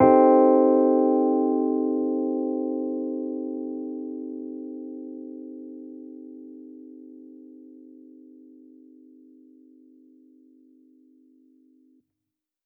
Index of /musicradar/jazz-keys-samples/Chord Hits/Electric Piano 2
JK_ElPiano2_Chord-Cm6.wav